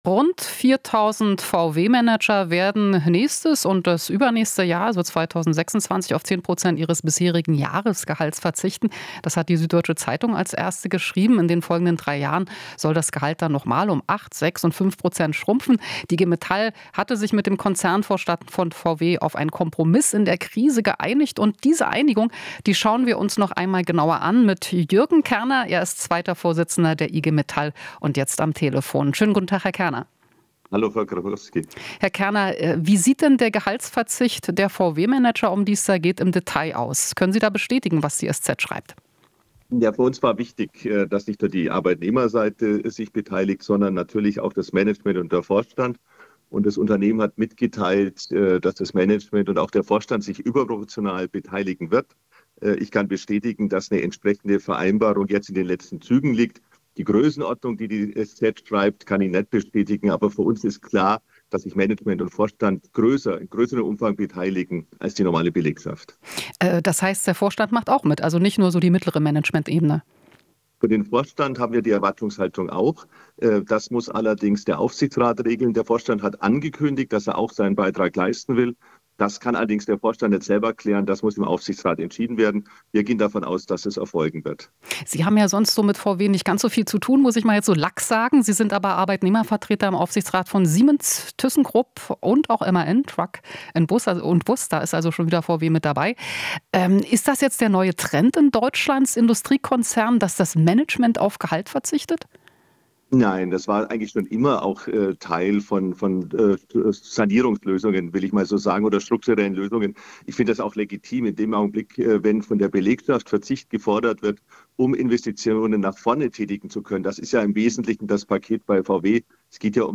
Interview - VW-Manager bekommen künftig weniger Gehalt